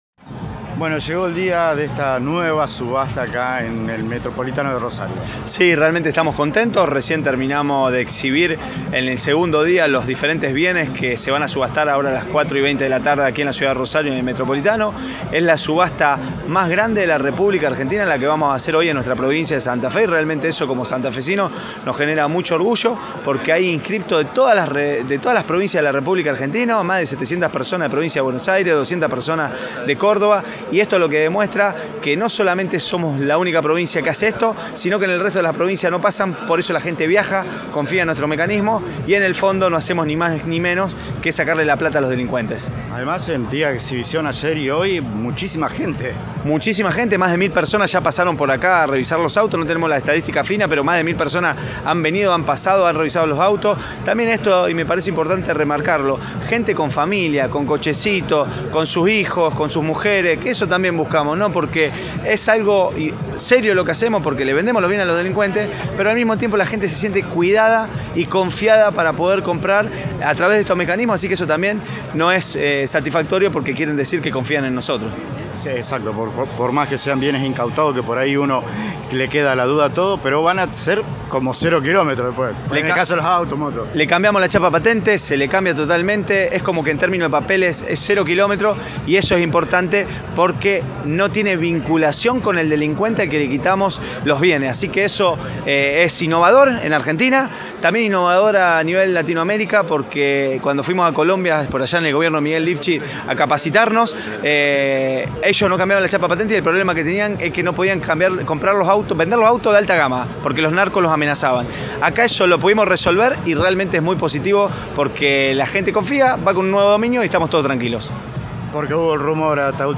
Secretario de Gestión Pública – Matías Figueroa Escauriza